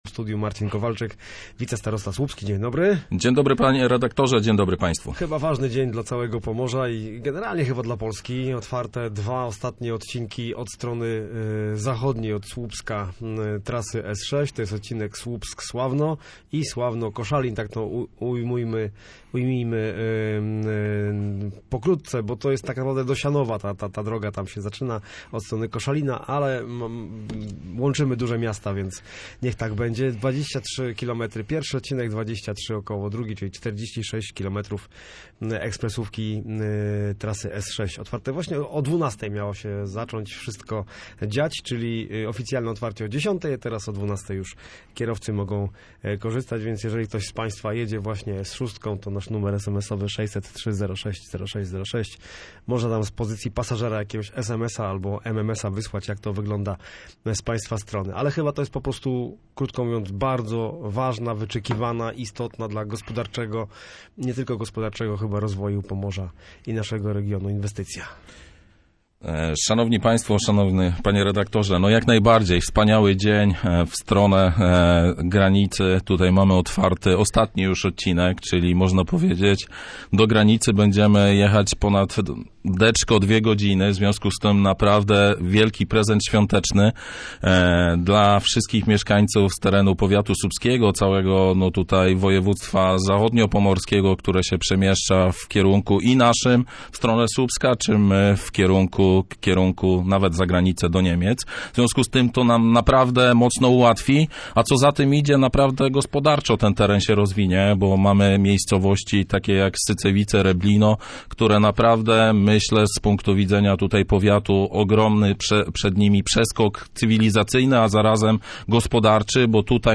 Mam nadzieję, że rok 2026 będzie przełomowy dla trasy Via Pomerania i rozbudowy portu w Ustce – mówił w Studiu Słupsk wicestarosta słupski Marcin Kowalczyk.